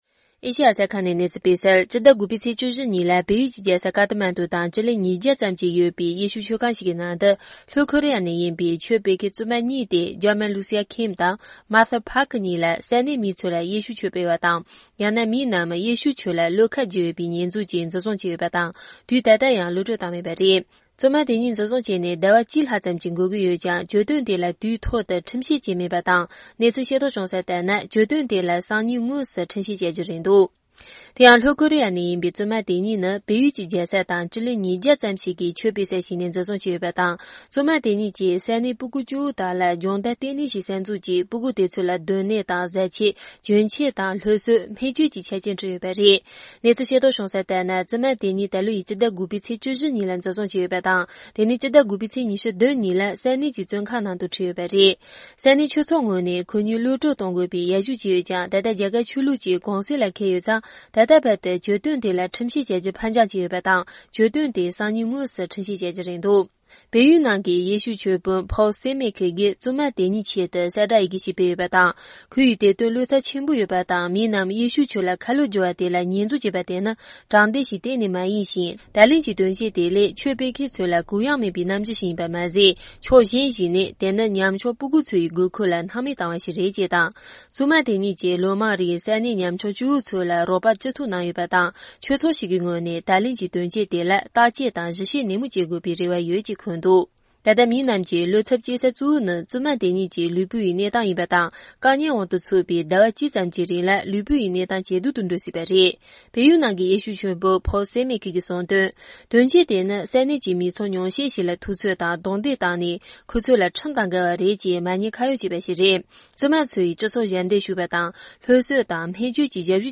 ཕབ་བསྒྱུར་དང་སྙན་སྒྲོན་ཞུ་ཡི་རེད།།